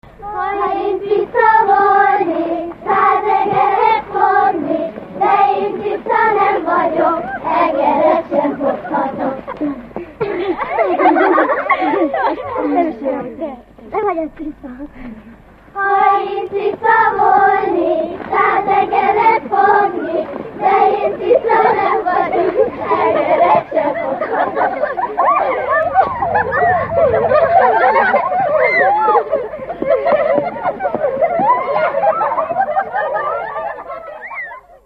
Alföld - Pest-Pilis-Solt-Kiskun vm. - Hévízgyörk
Előadó: Gyerekek, ének
Műfaj: Gyermekjáték
Stílus: 7. Régies kisambitusú dallamok
Szótagszám: 6.6.6.6
Kadencia: 3 (3) X 1